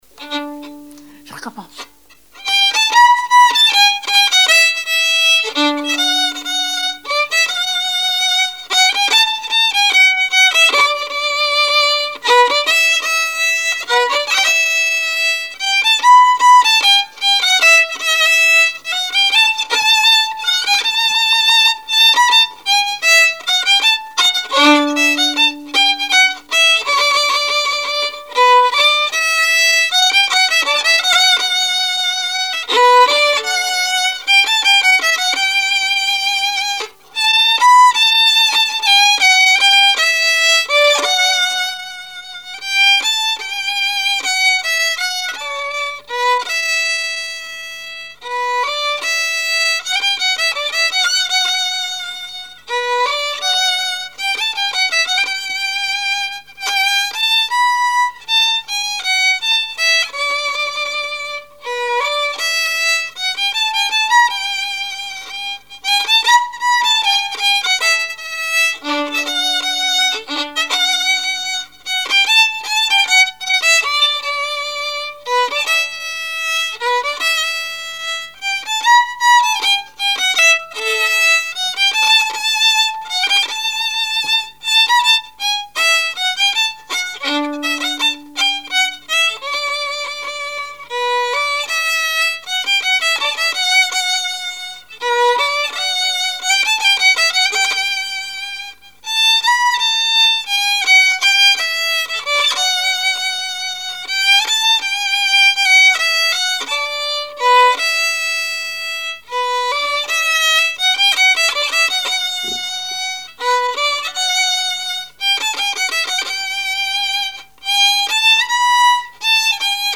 Saint-Christophe-du-Ligneron
danse : java
Répertoire musical au violon
Pièce musicale inédite